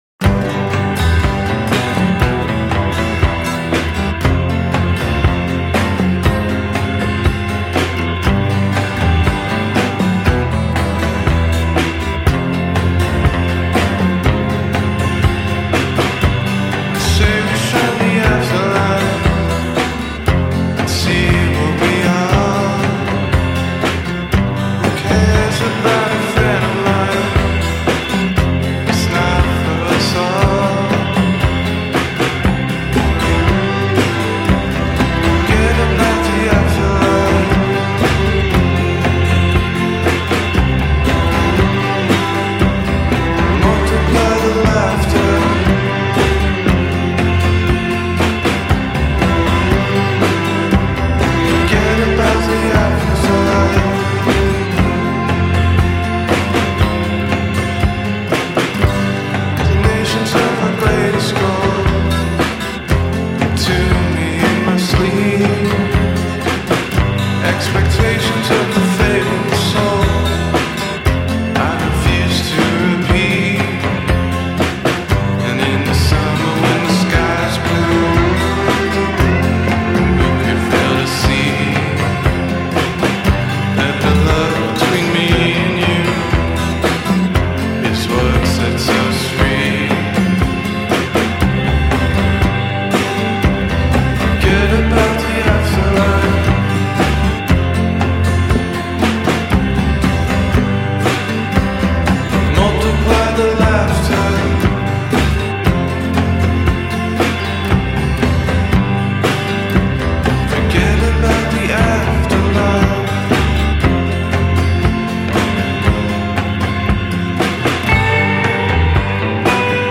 chitarra acustica e archi
Suoni psichedelici Anni Sessanta